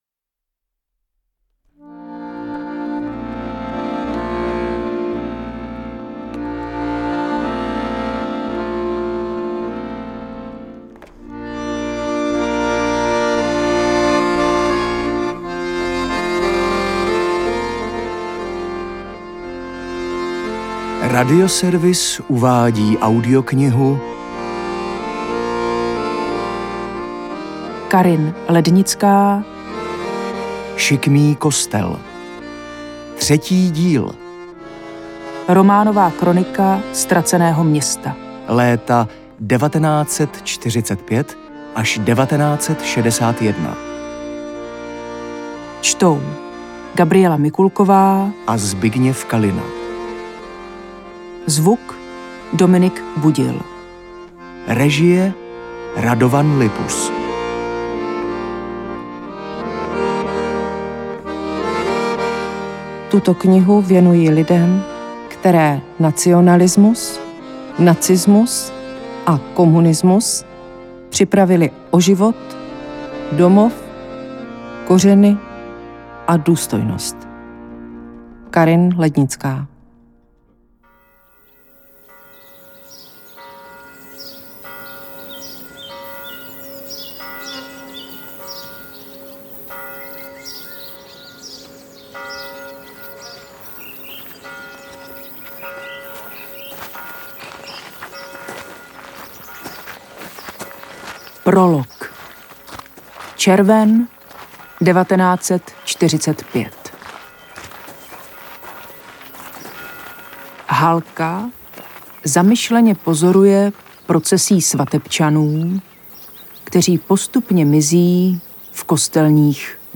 AudioKniha ke stažení, 84 x mp3, délka 30 hod. 51 min., velikost 2583,7 MB, česky